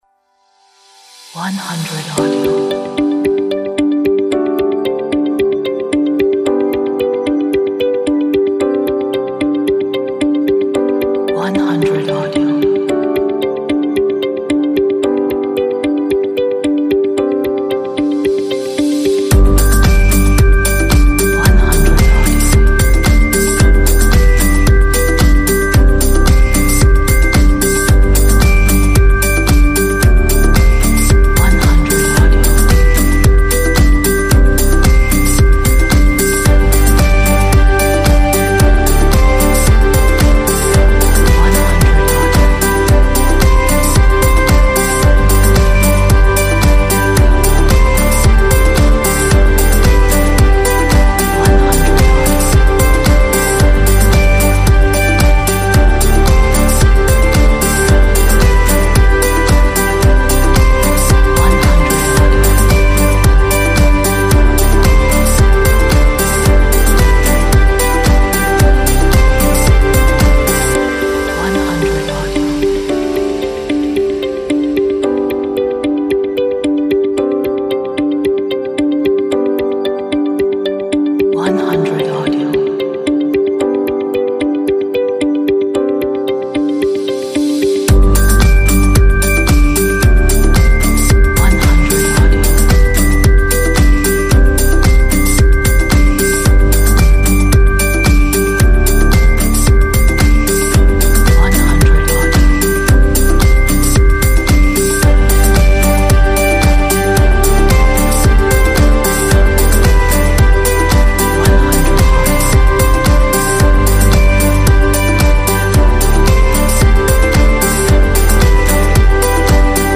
a nice pop upbeat track
很好的流行乐